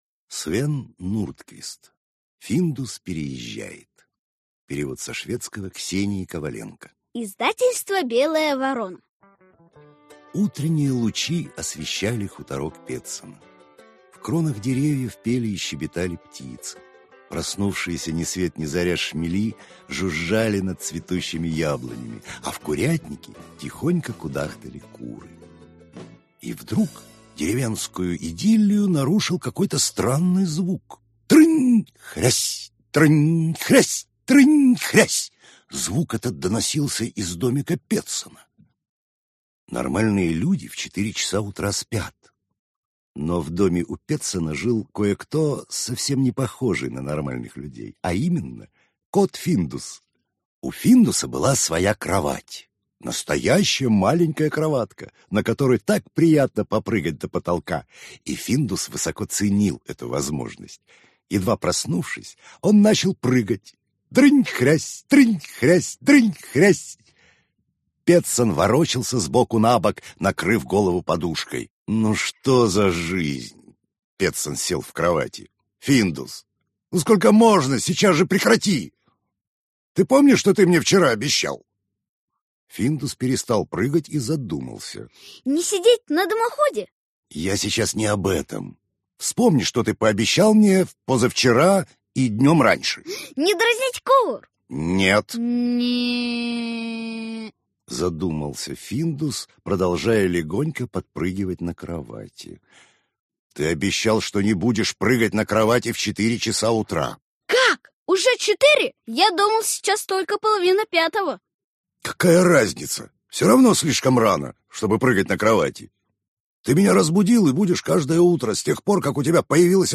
Аудиокнига Финдус переезжает | Библиотека аудиокниг